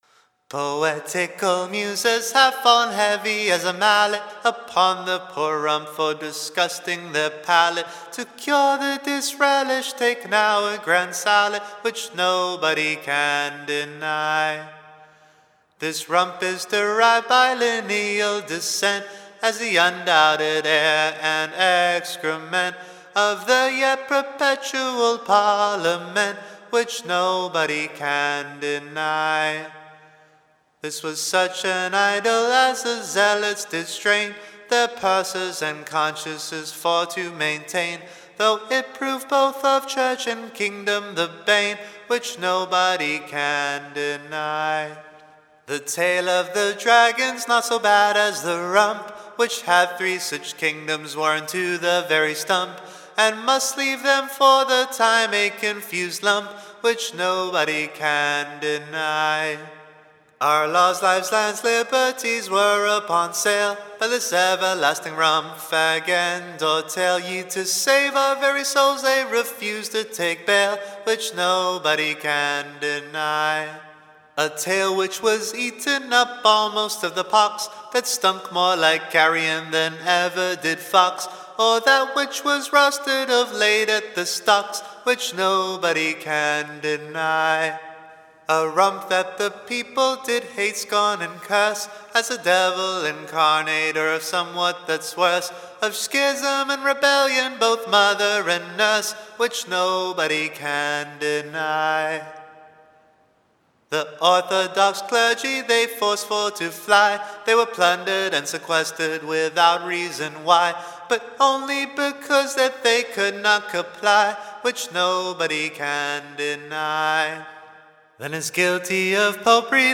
Recording Information Ballad Title The Rump serv'd in with a Grand Sallet. / OR, / A NEW BALLAD, Tune Imprint To the Tune of the Blacksmith.